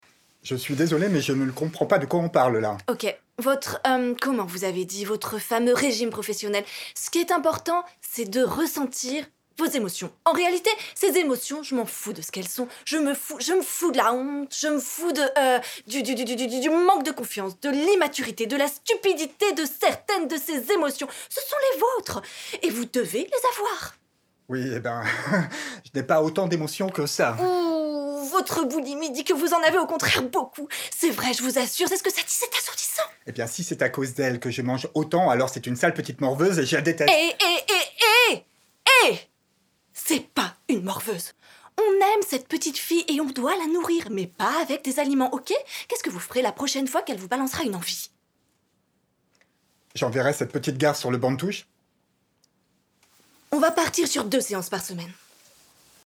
DOUBLAGE PSHYCHOLOGUE PARTICULIERE